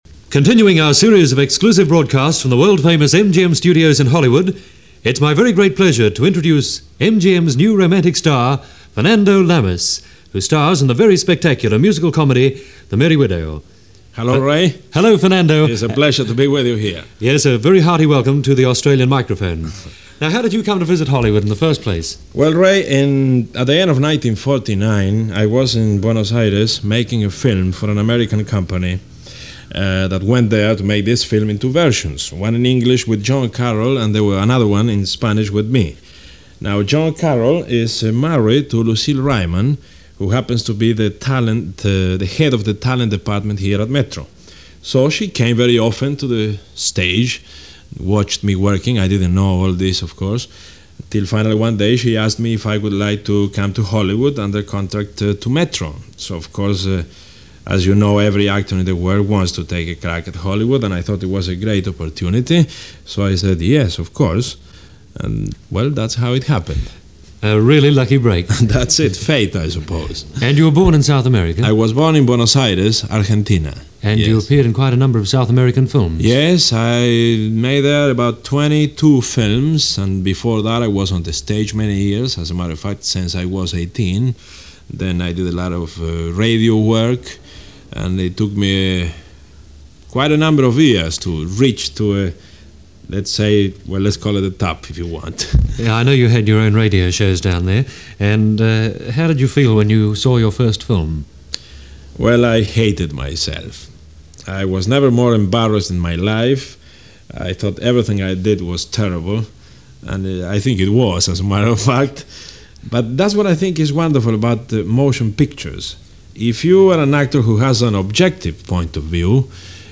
This interview takes place on September 26, 1952 for Australian radio.
Fernando-Lamas-Interview-1952.mp3